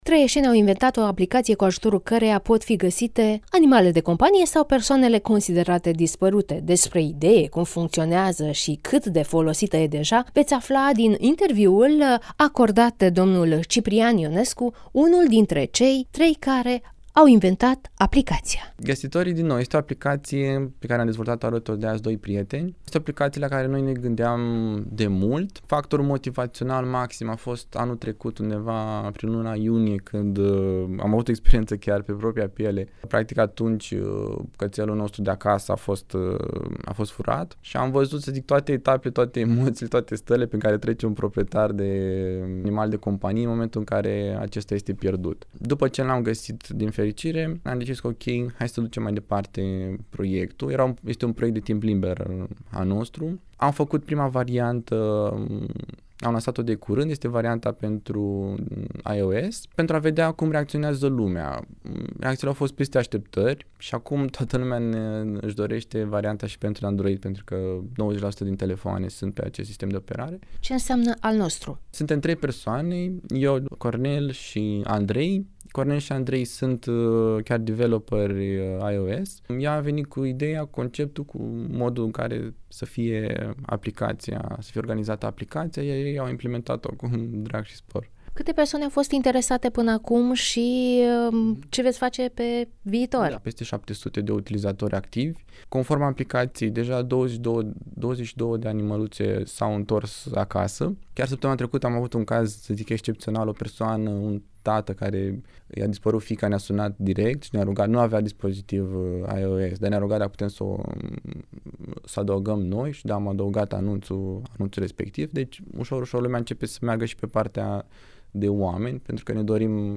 (INTERVIU) Aplicație pentru găsirea animalelor șia persoanelor dispărute